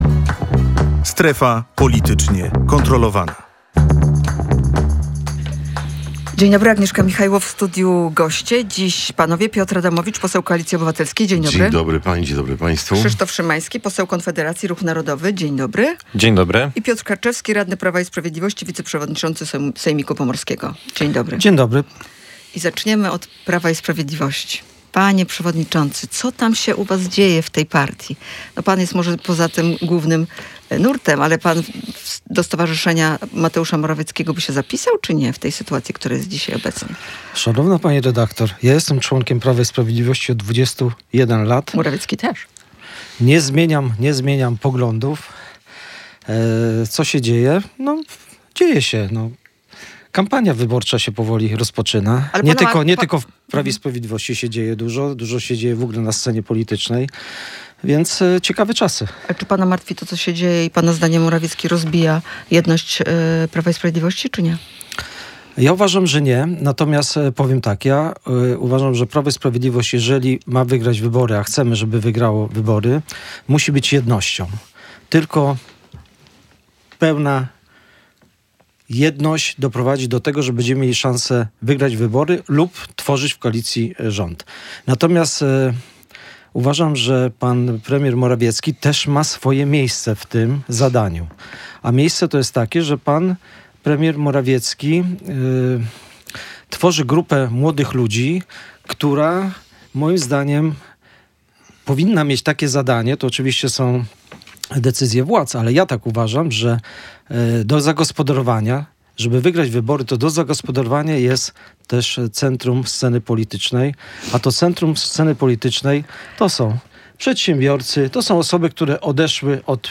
Wiceprezes Prawa i Sprawiedliwości Mateusz Morawiecki założył stowarzyszenie. Czy rozbije to jedność Prawa i Sprawiedliwości? Na ten temat rozmawiali goście